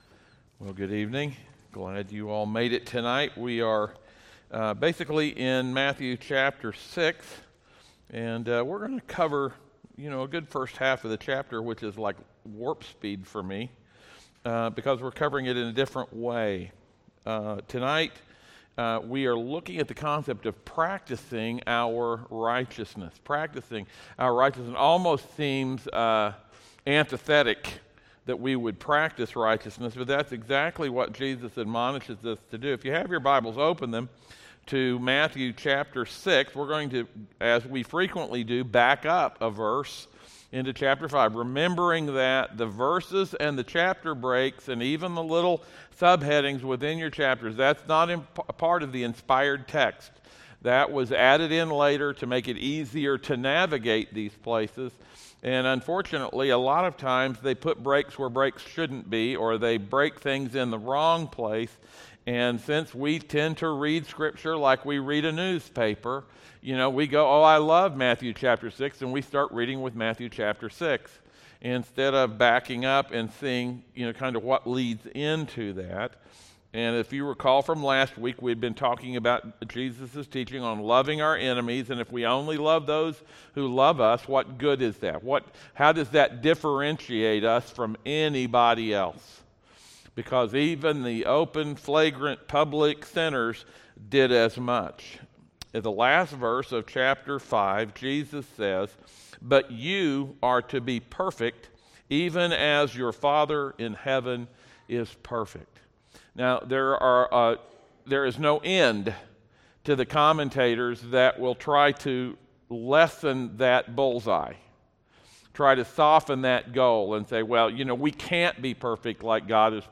Passage: Matthew 6 Service Type: audio sermons